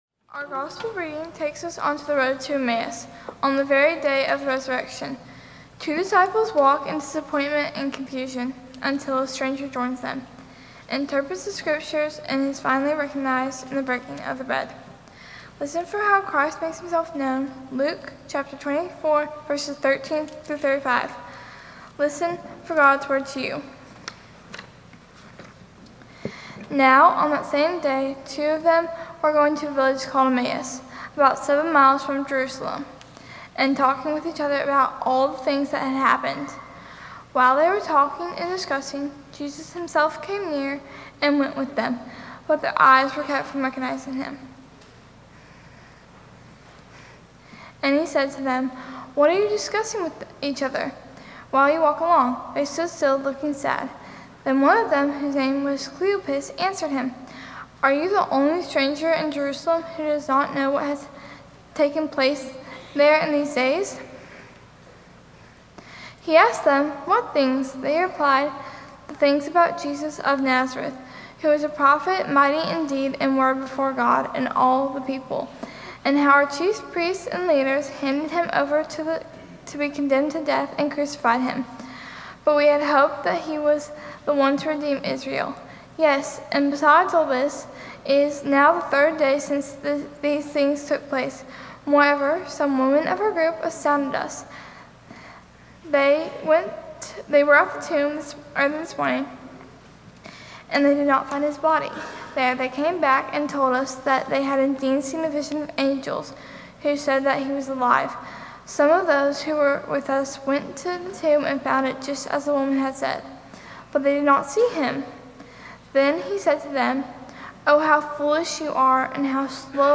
Sermon+Youth+Sunday+2026.mp3